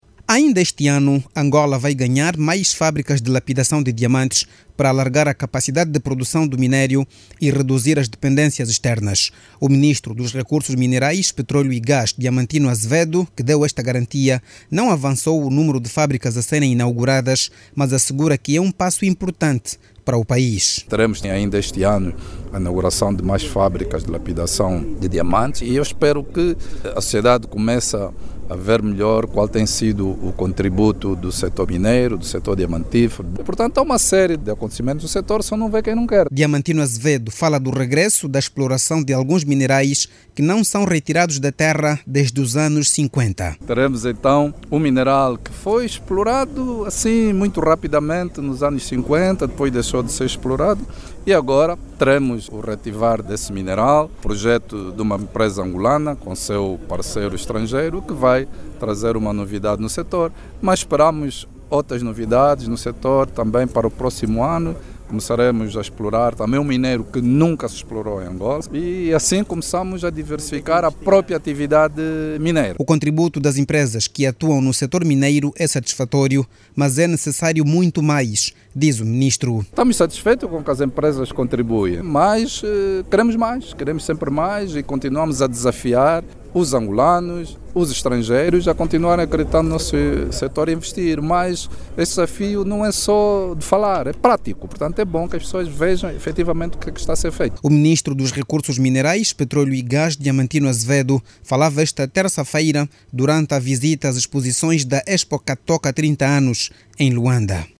O executivo, vai inaugurar ainda este ano, novas fábricas de lapidação de diamantes que poderá permitir a empregabilidade de mais jovens. Os desafios abrangem, também, a diversificação da actividade mineira com a introdução de novos minerais. Clique no áudio abaixo e ouça a reportagem